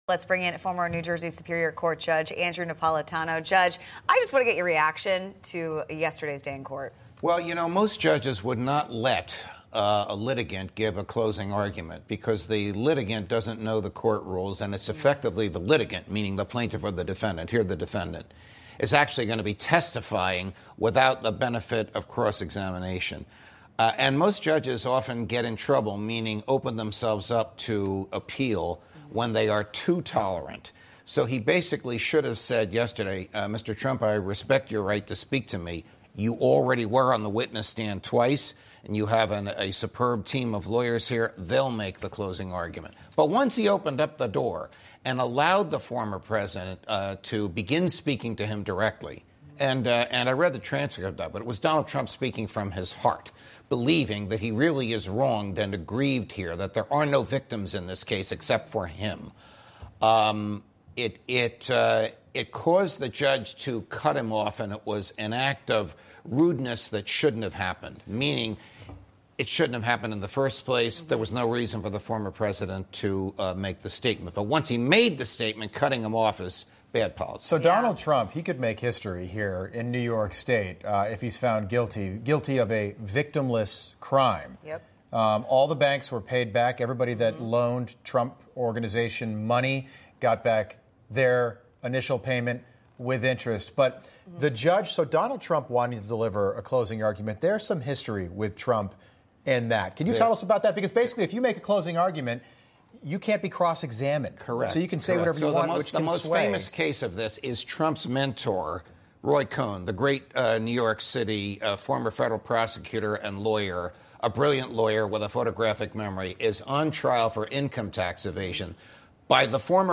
➡ Judge Andrew Napolitano discusses recent court proceedings involving former President Trump, criticizing the decision to allow him to give a closing argument, potentially opening up the case to appeal.